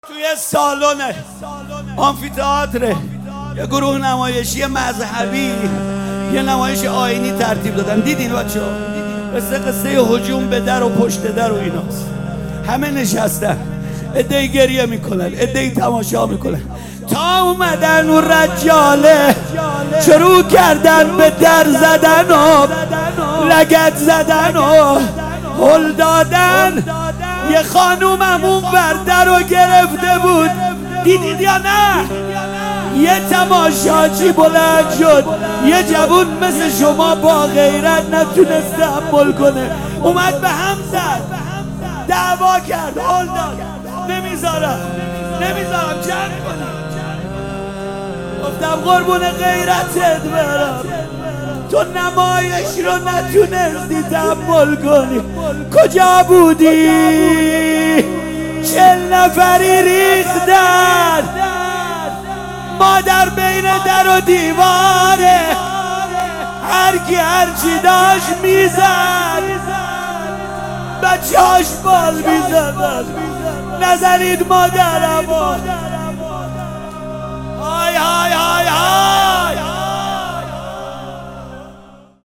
روضه فاطمیه
روضه سوزناک شهادت حضرت فاطمه زهرا سلام الله علیها